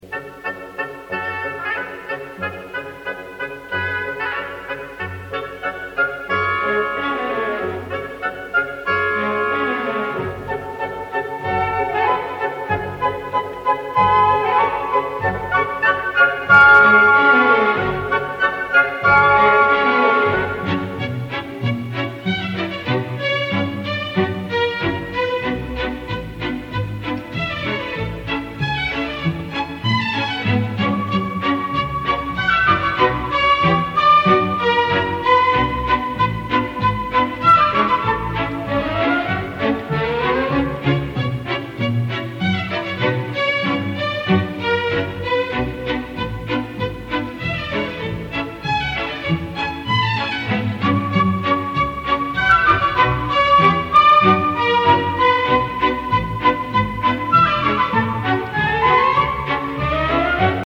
Категория: Классические рингтоны